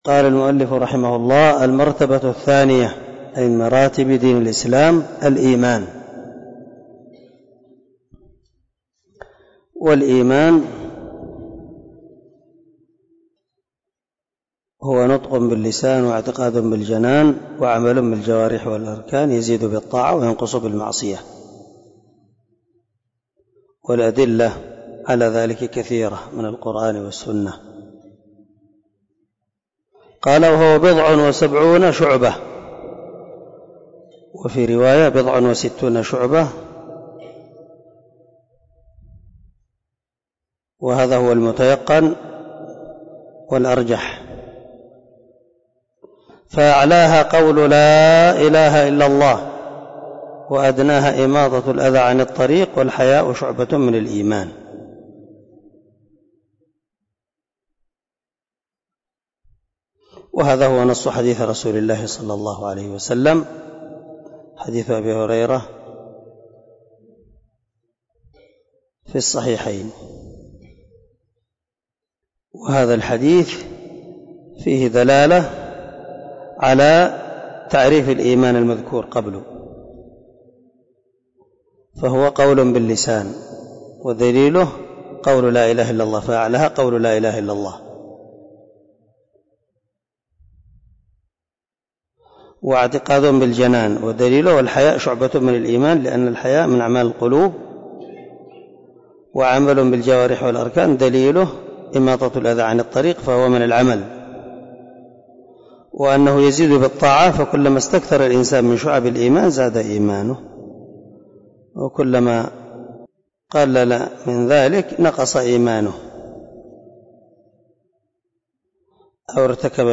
🔊 الدرس 28 من شرح الأصول الثلاثة